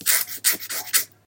scratch.mp3